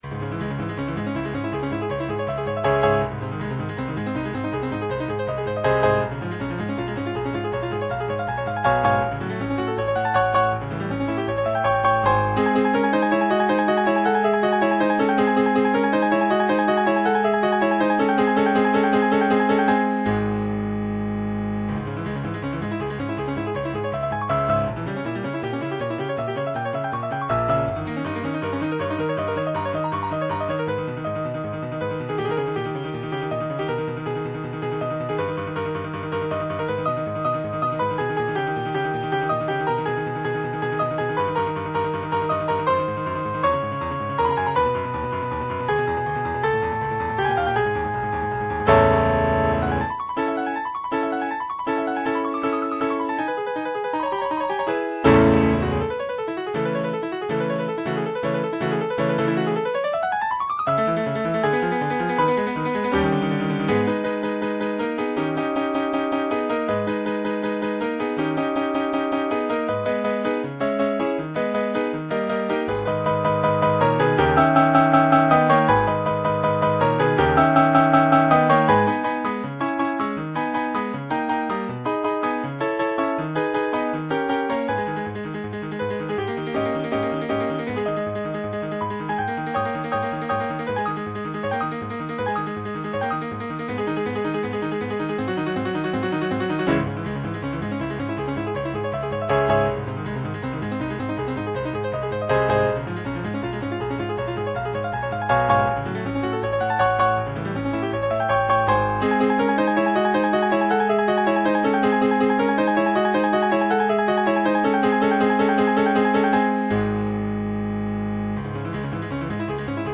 Background music for your enjoyment: Ludwig van Beethoven - Sonata No 14 in C# minor, Presto